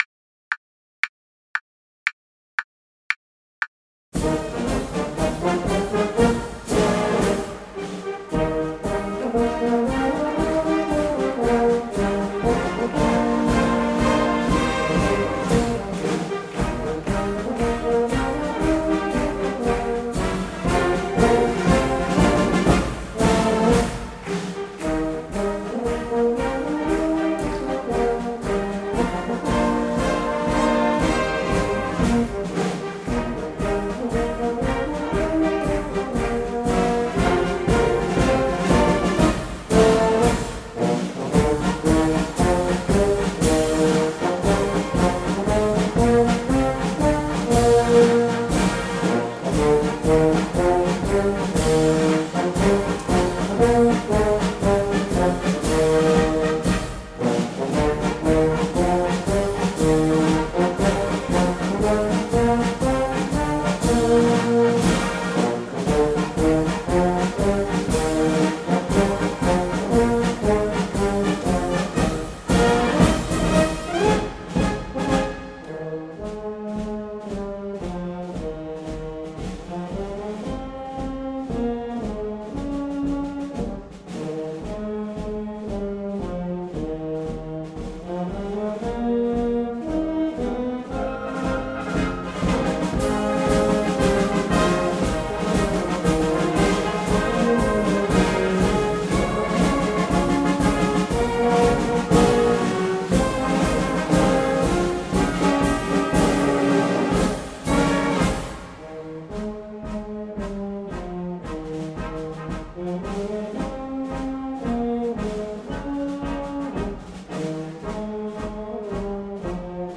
Playalong herunterladen und vielleicht 1-2 mal anhören (Bei der Aufnahme wird 4 Takte, sprich 8 Schläge eingezählt.)
• Instrument stimmen (auf 442 Hz)
Wolsfelder Marsch mit Klick.mp3